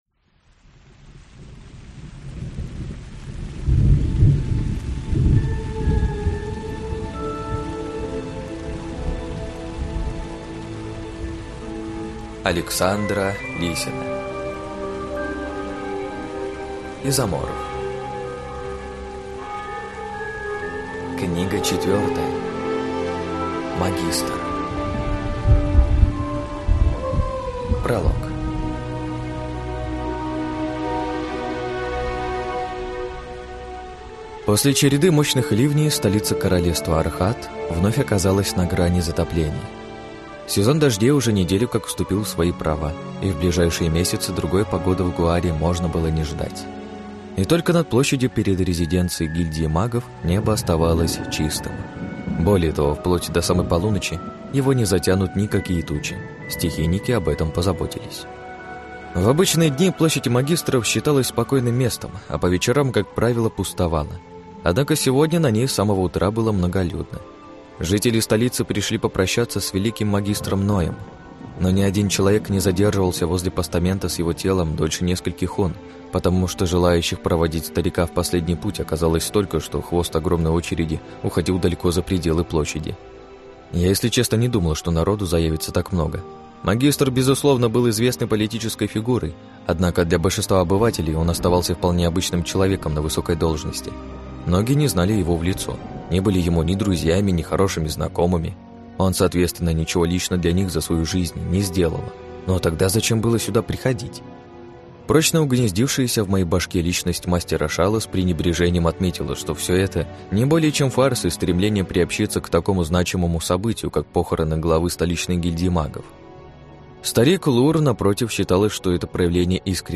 Аудиокнига Изоморф. Магистр | Библиотека аудиокниг